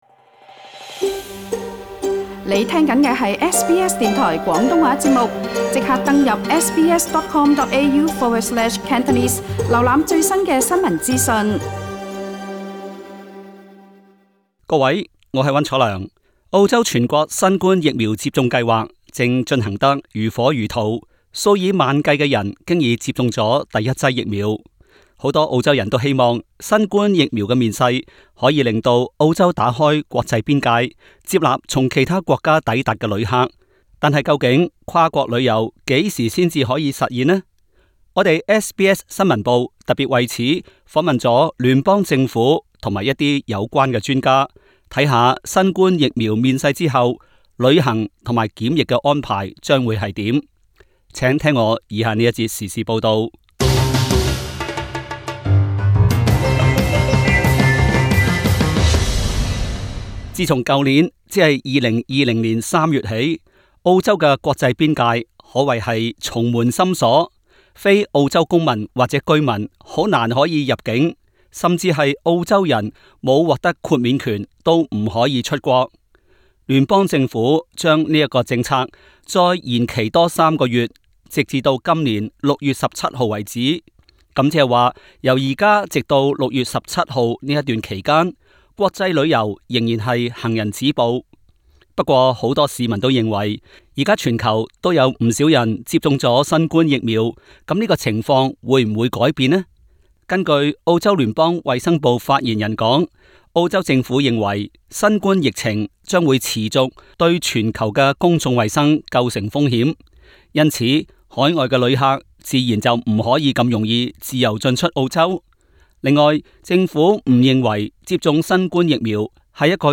SBS廣東話節目
SBS 新聞部訪問過聯邦衛生部官員和業界從業員，看看有關情況。